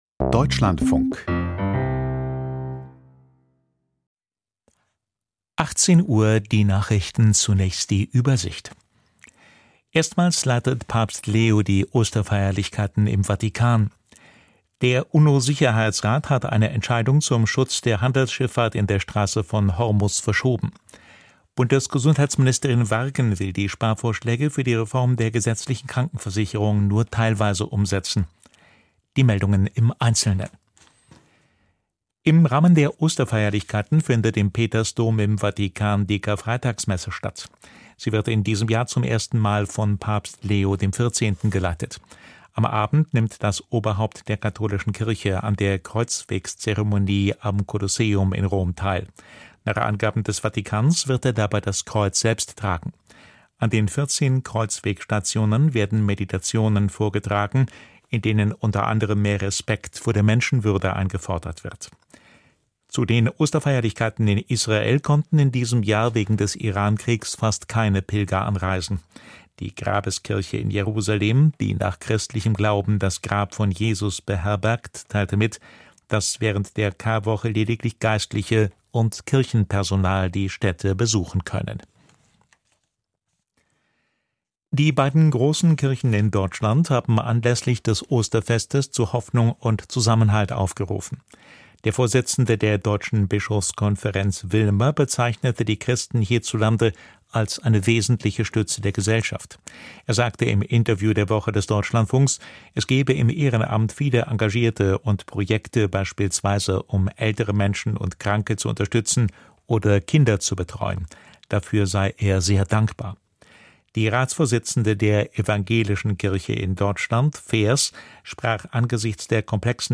Die Nachrichten vom 03.04.2026, 18:00 Uhr
Aus der Deutschlandfunk-Nachrichtenredaktion.